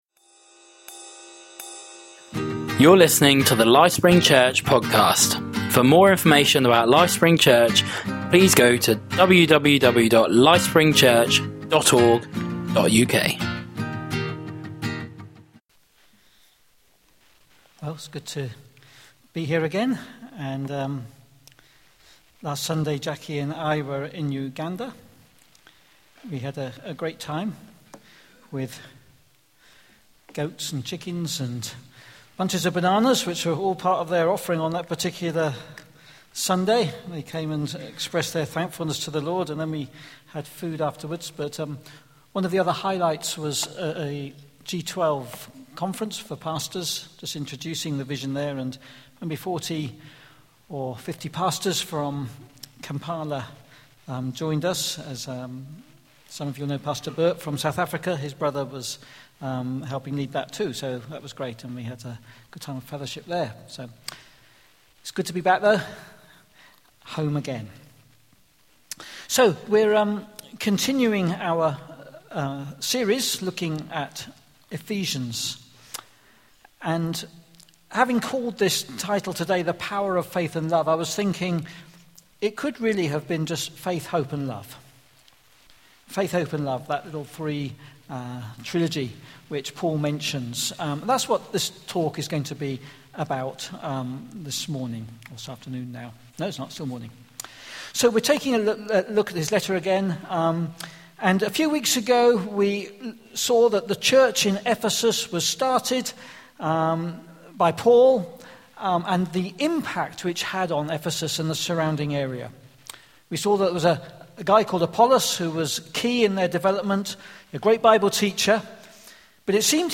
Listen back to all Sunday morning talks below.